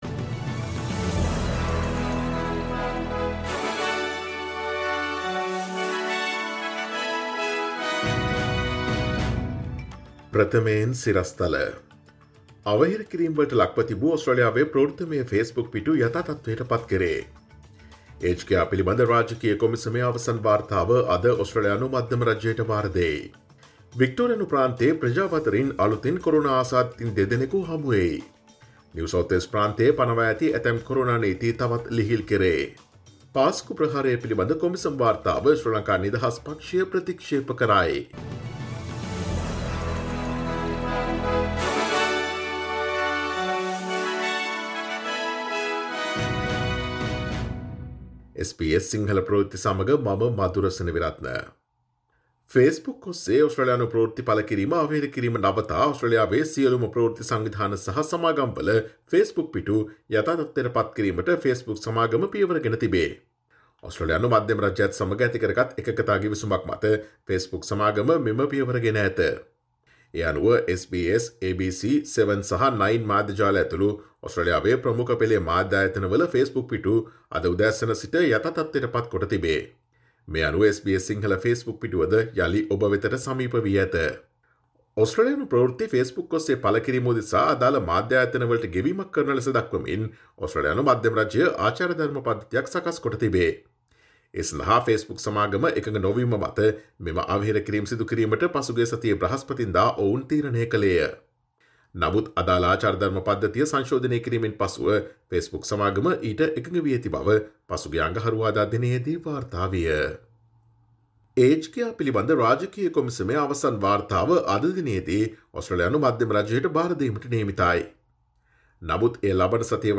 Today’s news bulletin of SBS Sinhala radio – Friday 26 February 2021.